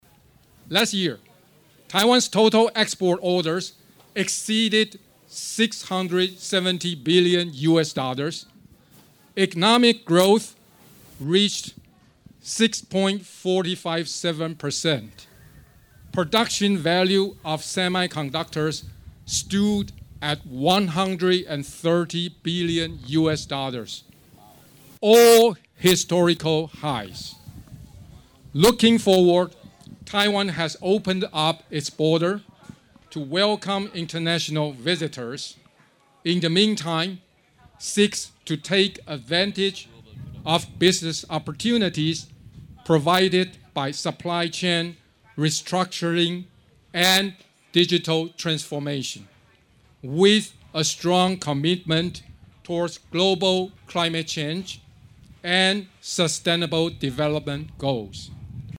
Ambassador Lan was addressing the gathering at the Taiwan Sports Day, hosted by the Taiwanese Embassy last Saturday October 8th, in collaboration with the Ministry of the Public Service Consumer Affairs and Sports.
During his address, Ambassador Lan said Taiwan is continuing to progress in many areas.